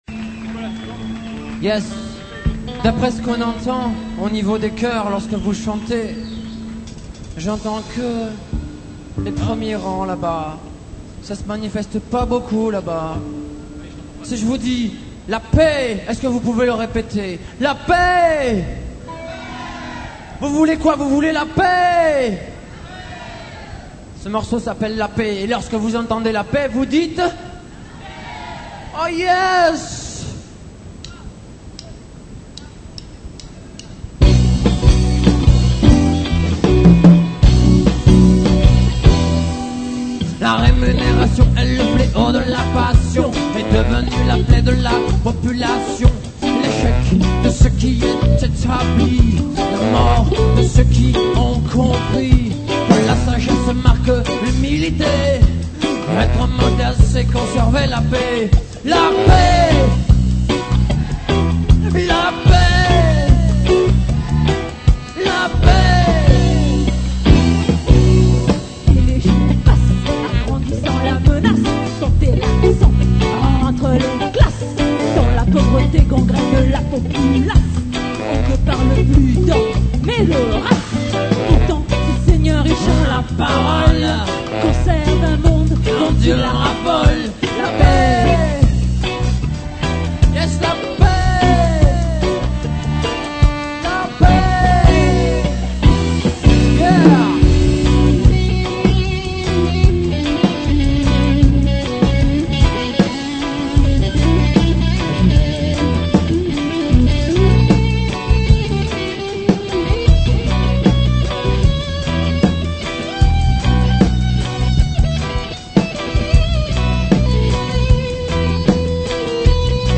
reggea